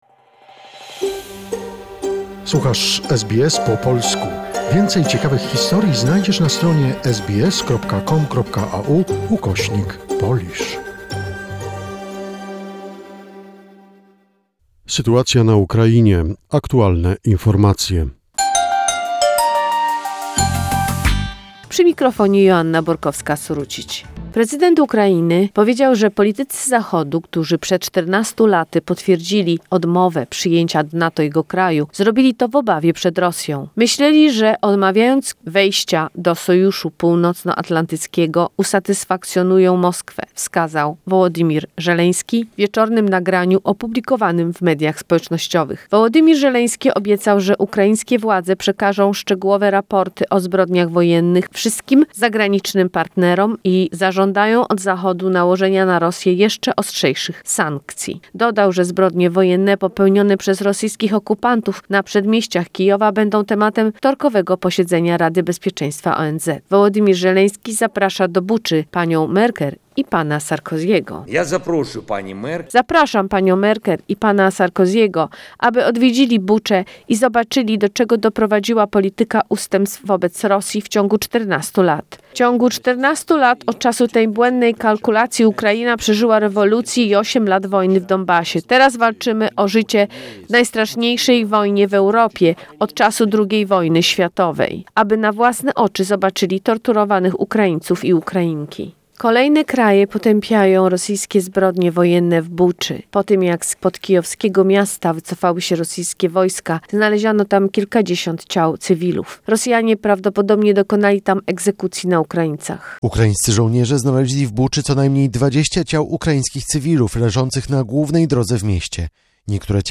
The most recent information about the situation in Ukraine, a short report prepared by SBS Polish